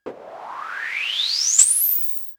Machine17.wav